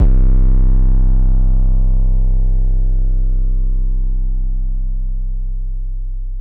Metro 808s [Custom].wav